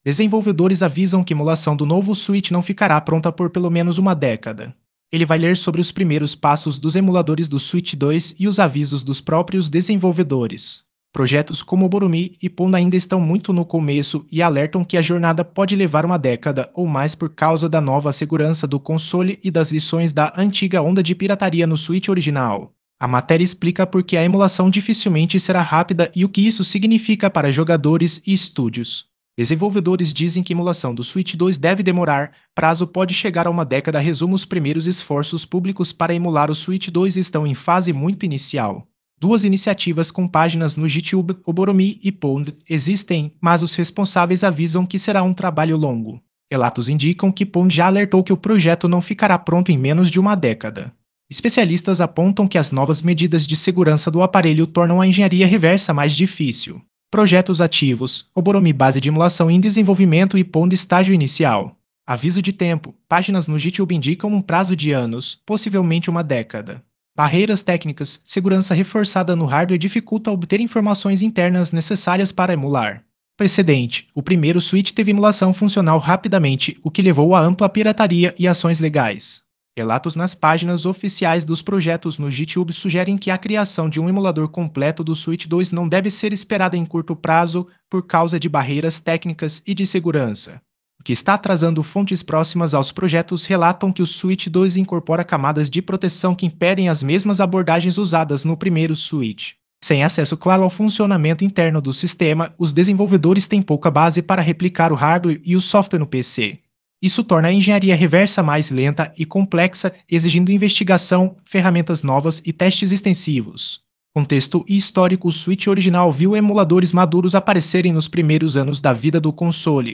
Ele vai ler sobre os primeiros passos dos emuladores do Switch dois e os avisos dos próprios desenvolvedores.